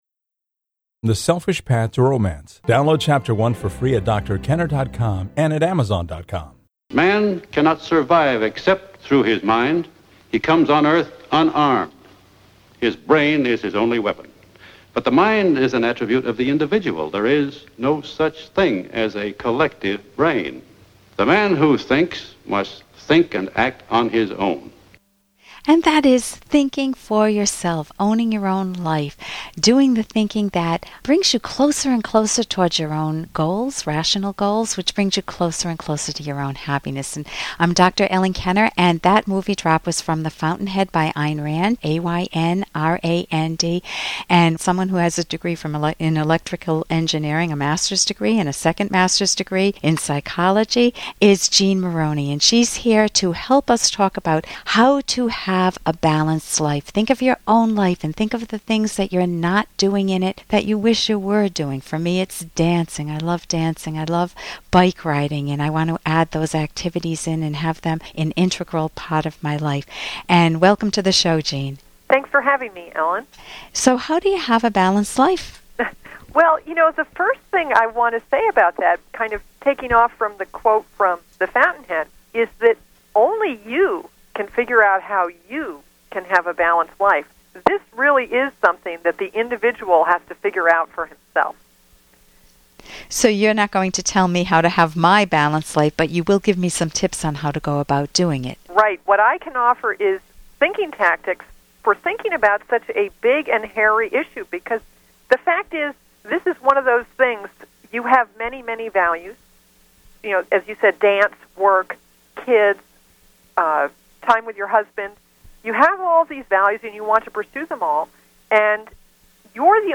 A Balanced Life ~ Finding a balance between work, romance, and all aspects of your life - a short interview with seminar presenter